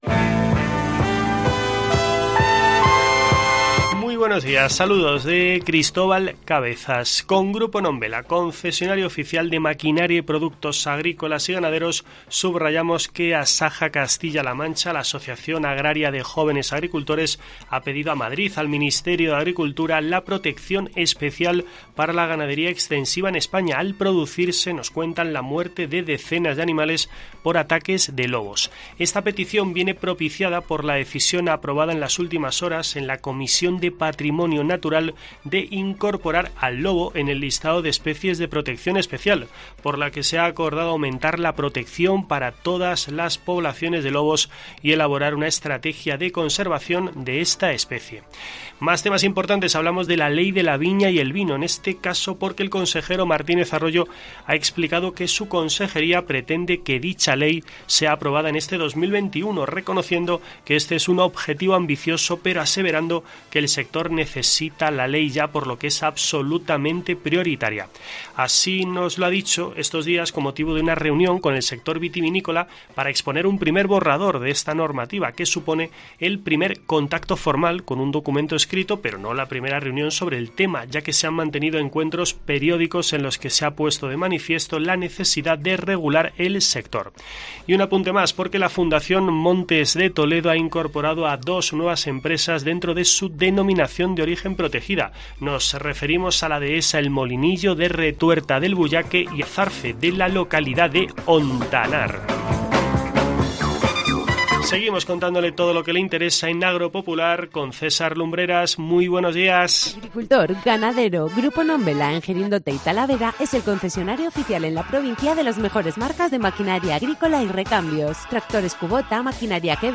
Escucha en la parte superior de esta noticia toda la actualidad del mundo del campo en nuestro boletín informativo semanal.